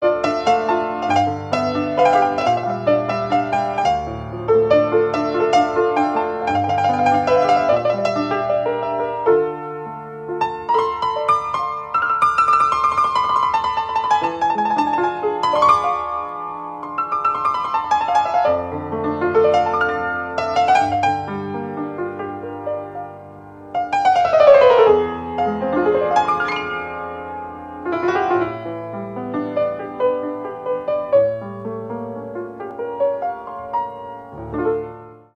пианино
инструментальные
без слов